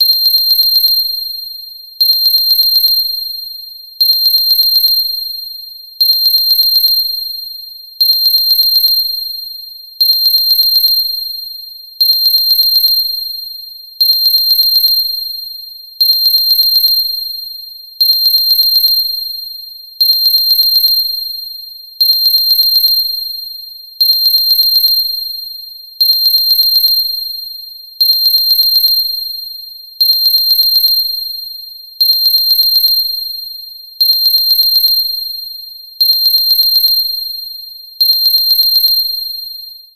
高音のベル音が起こしてくれるアラーム音。